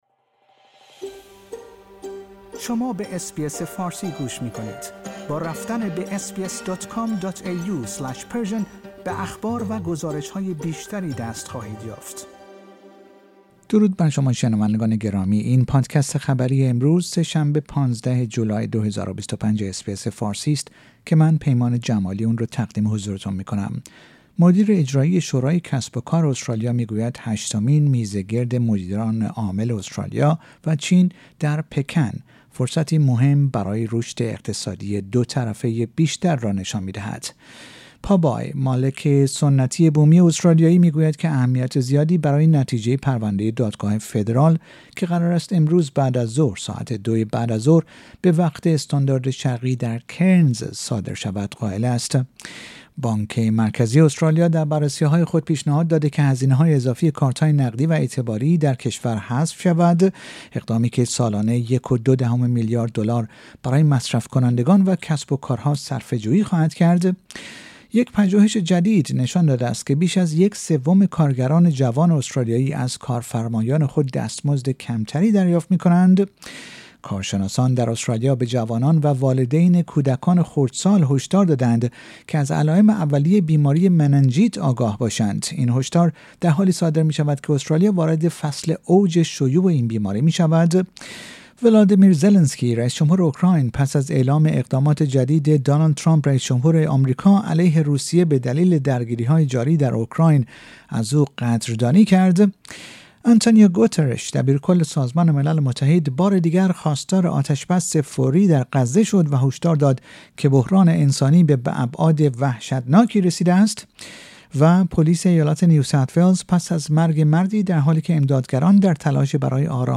در این پادکست خبری مهمترین اخبار امروز سه شنبه ۱۵ جولای ارائه شده است.